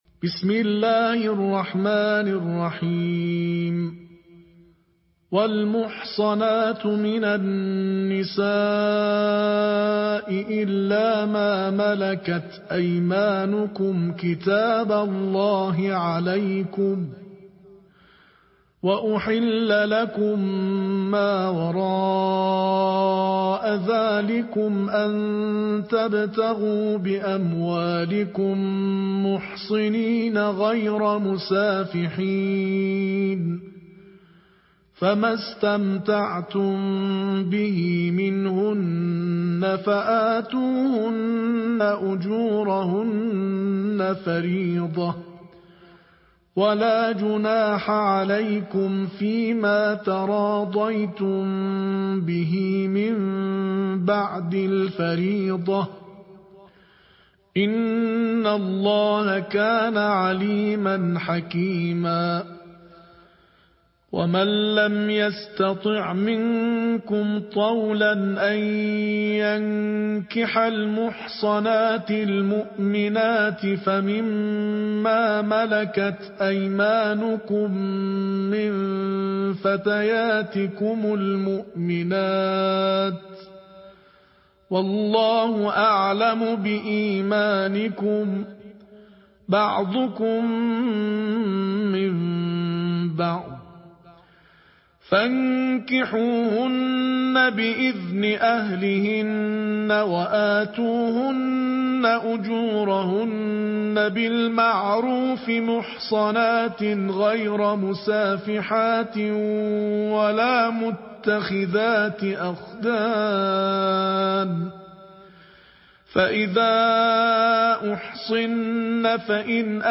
د قرآن د پنځمې(۵) سپارې یا جزوې د ترتیل قرائت
دغه تلاؤتونه د تیر کال(۱۴۴۵قمری کال) د روژې په مبارکې میاشتې شپو ورځو کې د تهران د خاتم الانبیاء روغتون د کلتوری برخې له خوا د روغتون په لمانځ کوټې کې اجرا او ریکارډ شوی او د لومړي ځل لپاره خپریږي.